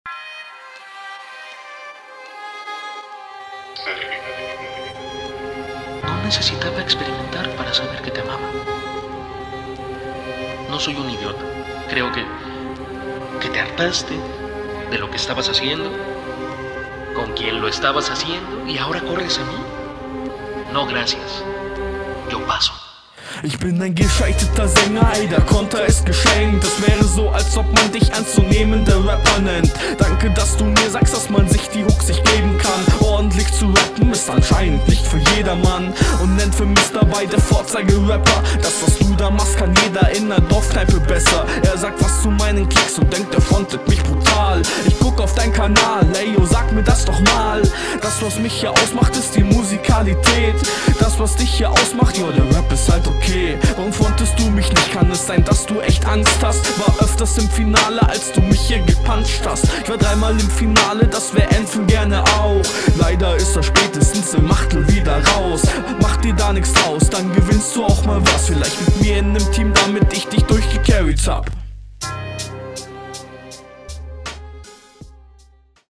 Mische diesmal wieder nicht meines.